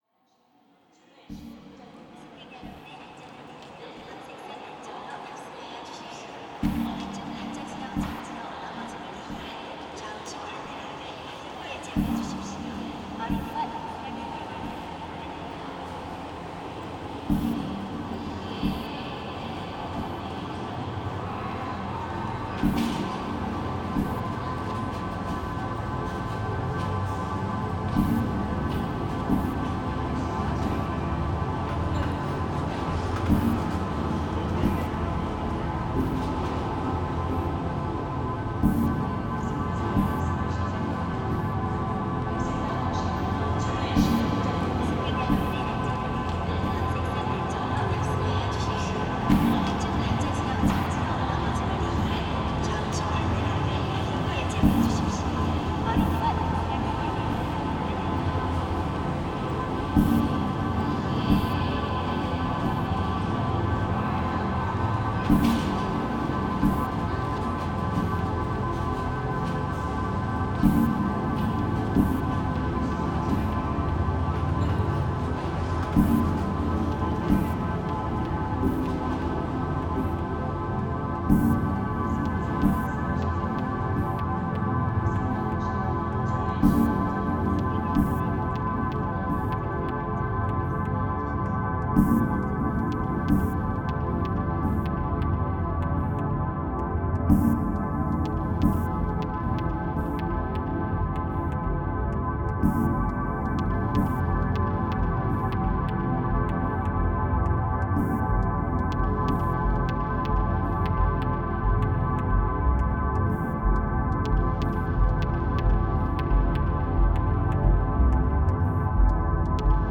Genre: Ambient/Dub Techno.